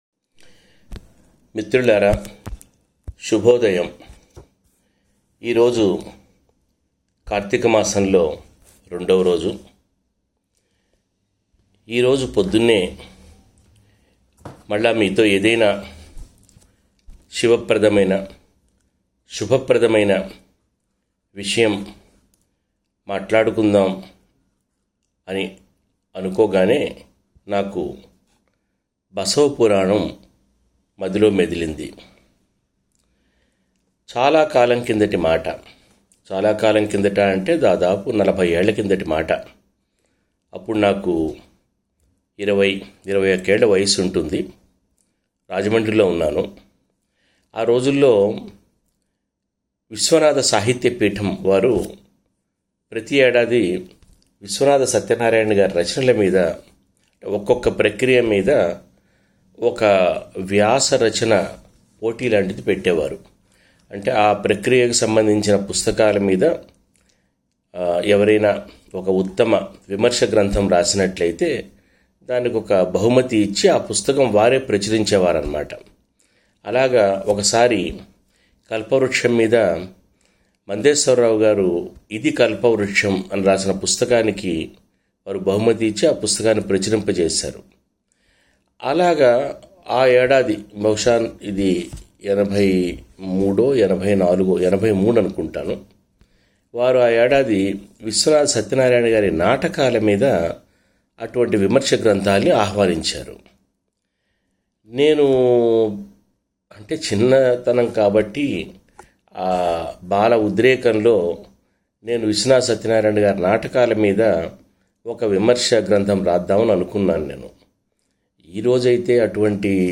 నలభై నిమిషాల ప్రసంగం.